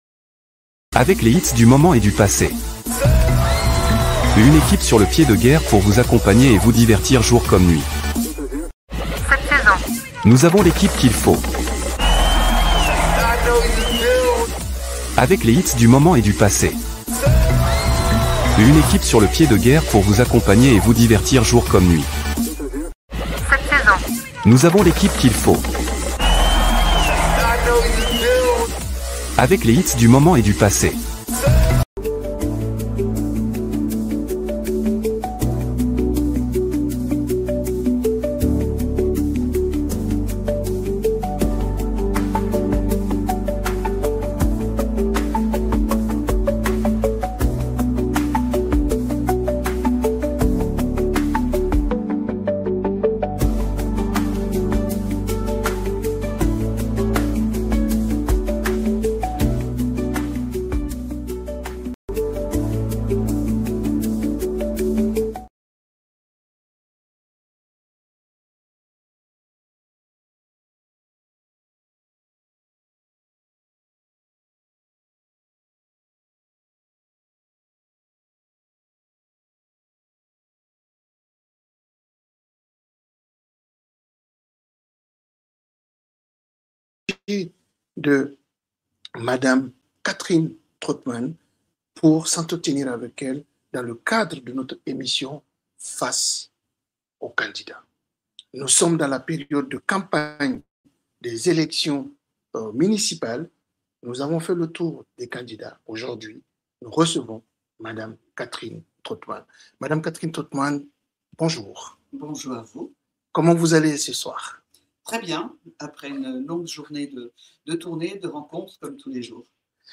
Nous avons reçu en direct Catherine TRAUTMANN Candidate aux élections municipales de Strasbourg.
Au cours de cette émission, il a présenté : Son parcours et son engagement Sa vision pour Strasbourg Les priorités de son programme Et a répondu aux questions des auditeurs et des citoyens Cet échange direct, retransmis en live sur nos réseaux sociaux, a permis d’aborder les enjeux locaux : cadre de vie, sécurité, développement économique, services publics de proximité, jeunesse et cohésion sociale.